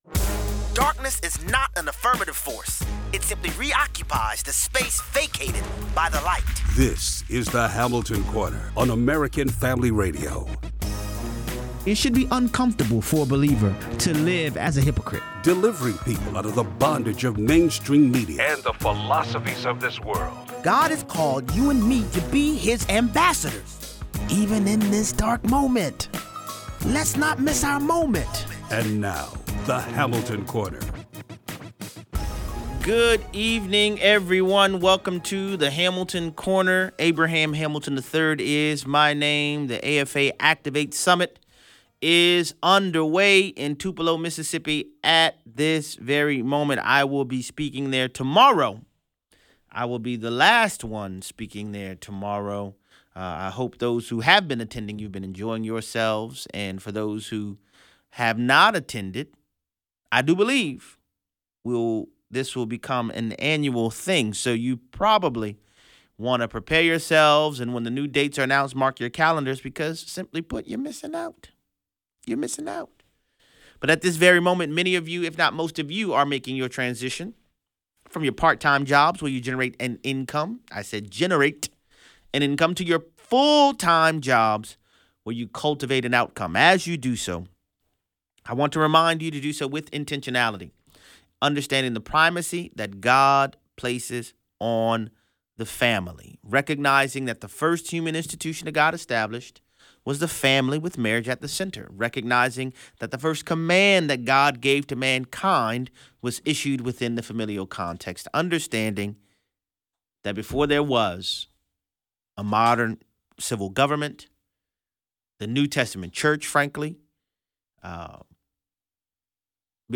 The Five Languages of Apology | Interview 33:06